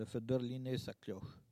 Il fait sonner sa cloche
cloche(s), carillonneur(s)
Saint-Hilaire-de-Riez